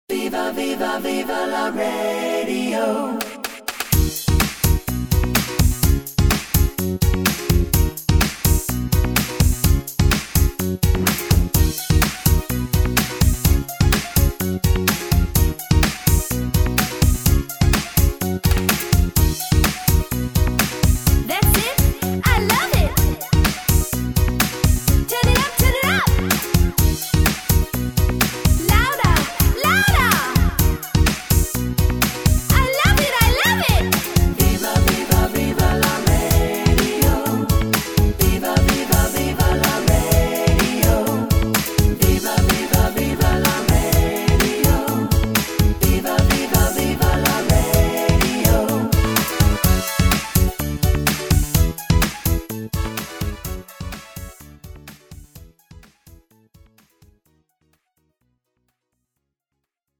팝송) MR 반주입니다.